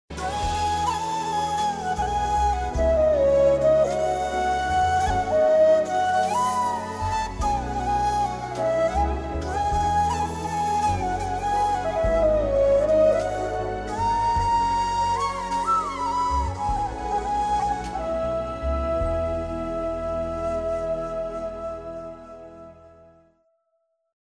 CategoryFlute / Instrumental
• Relaxing instrumental tone